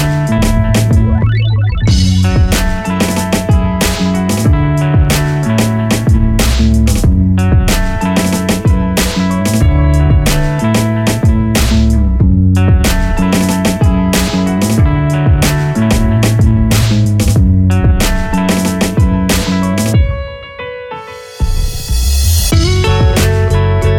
For Solo Male Pop (2000s) 2:57 Buy £1.50